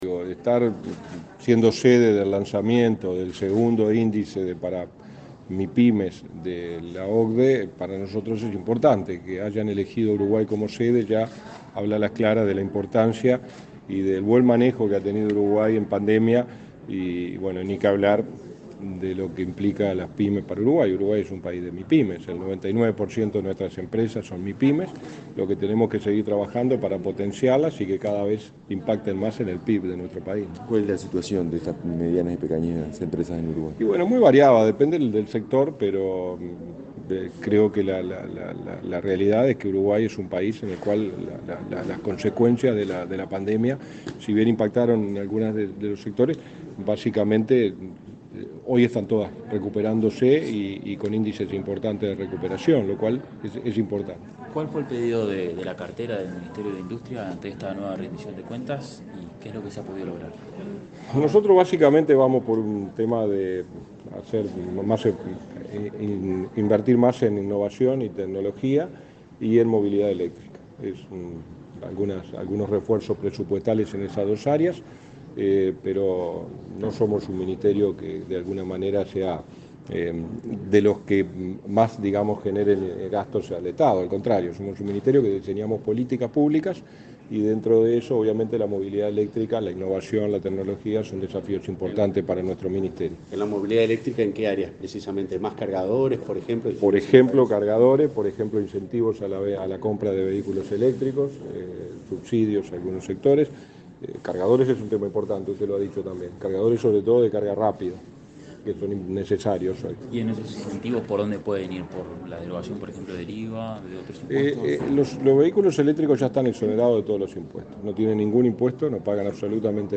Declaraciones a la prensa del subsecretario de Industria, Walter Verri
Declaraciones a la prensa del subsecretario de Industria, Walter Verri 27/06/2022 Compartir Facebook X Copiar enlace WhatsApp LinkedIn El subsecretario de Industria, Walter Verri, participó en el lanzamiento de la segunda edición del índice de políticas públicas para mipymes en América Latina y el Caribe, realizado este lunes 17 en Montevideo. Luego, dialogó con la prensa.